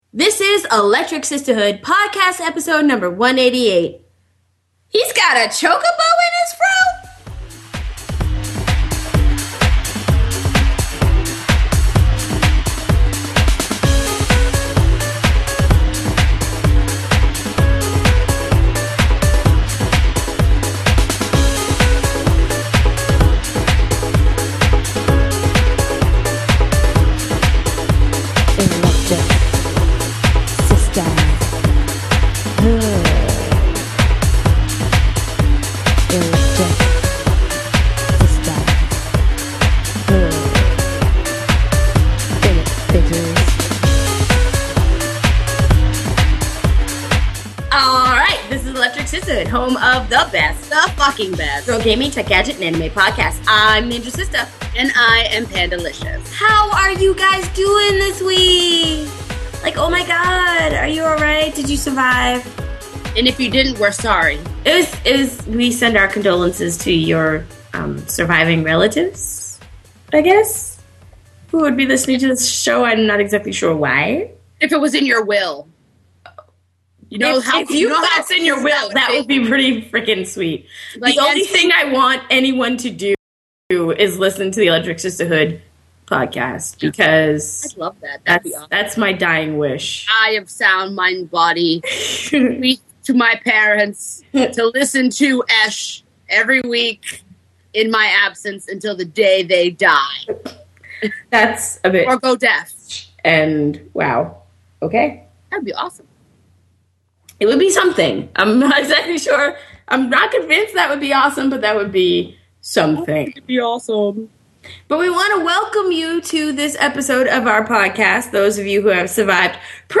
In this episode of the podcast the ladies chat about a few different games and get into it about cheating.